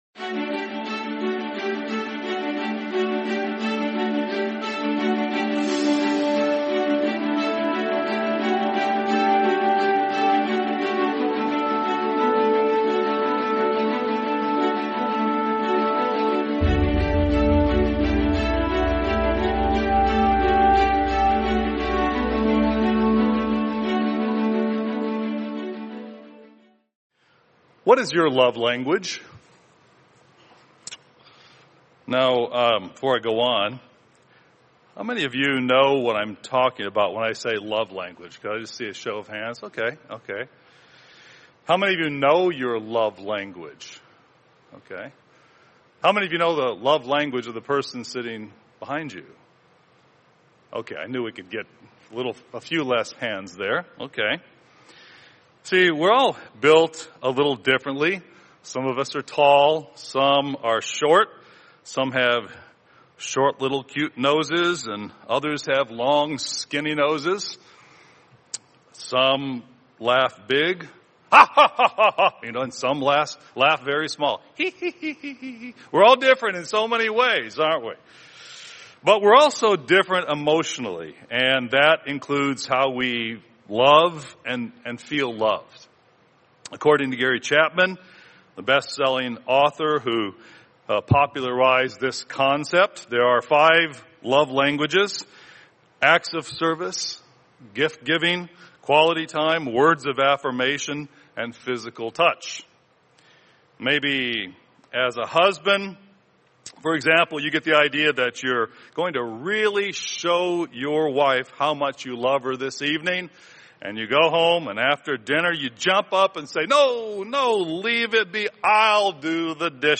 God's Love Language | Sermon | LCG Members